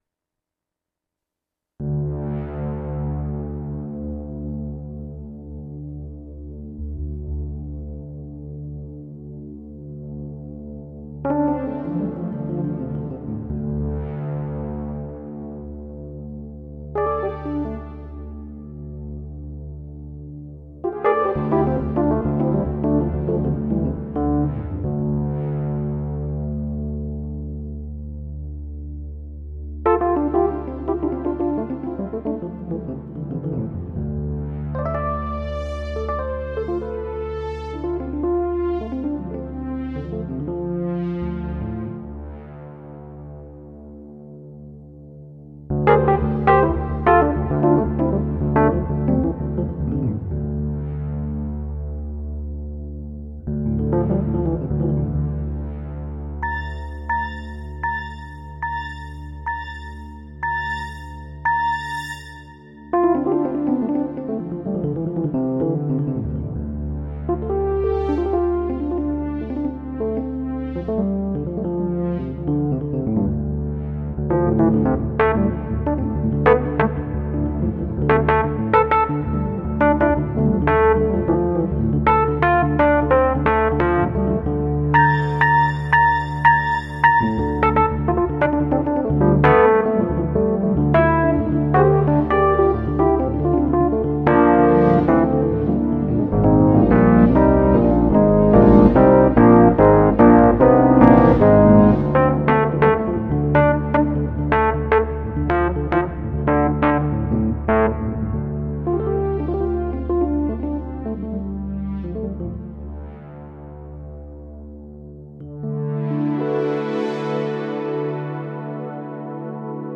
pianist/componist
Hij heeft bij verschillende kunstwerken speciale composities geschreven die op deze middag live gespeeld werden.